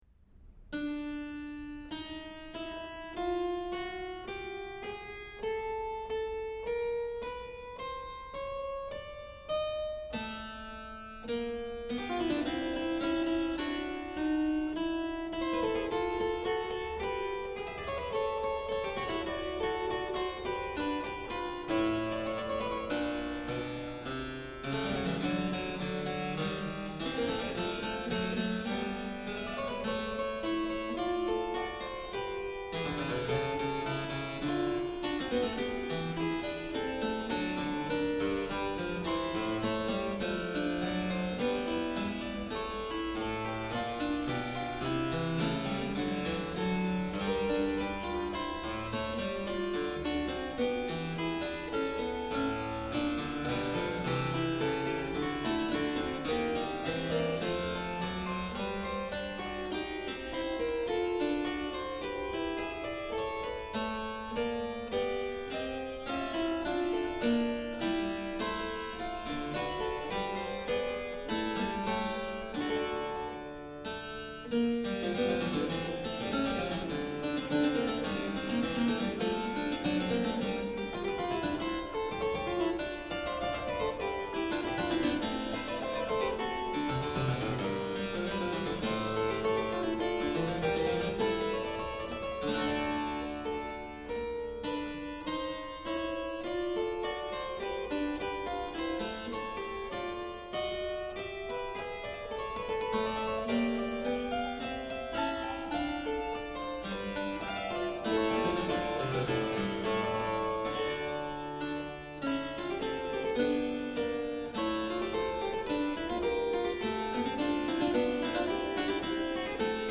Even a fraction of a comma is easily audible.
harpsichordist